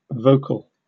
Ääntäminen
RP : IPA : /ˈvəʊ.kəl/ GA : IPA : /ˈvoʊ.kəl/